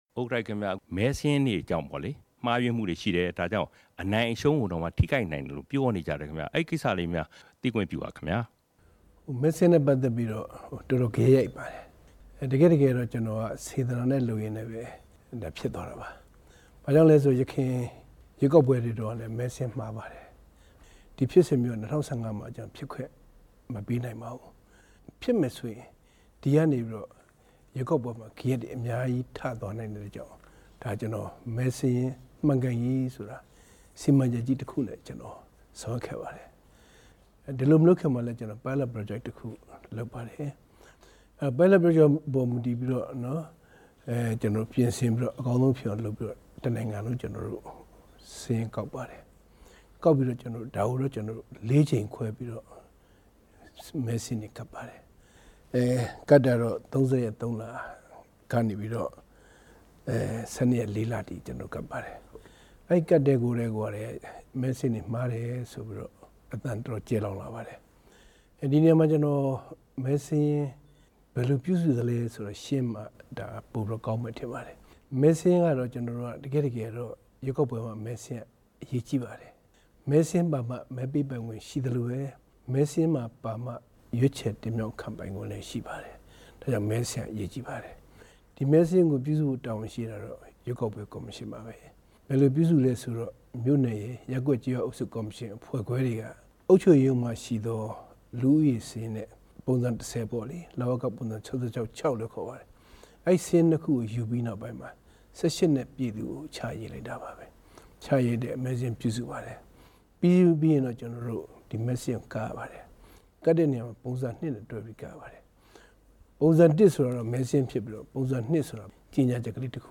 RFA နဲ့ မနေ့ ညနေပိုင်းက သီးသန့်တွေ့ဆုံမေးမြန်းရာမှာ ကော်မရှင် ဥက္ကဌ ဦးတင်အေးက အခုလိုပြော တာဖြစ်ပါတယ်။